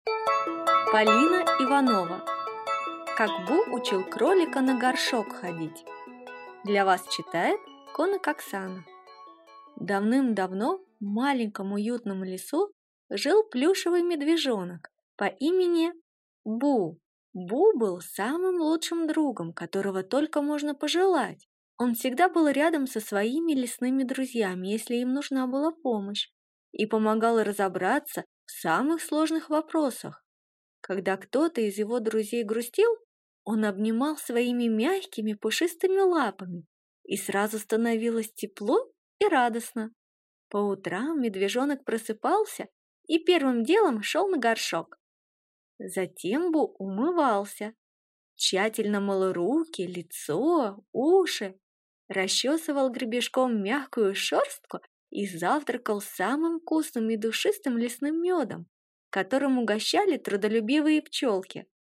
Аудиокнига Как Бу учил Кролика на горшок ходить | Библиотека аудиокниг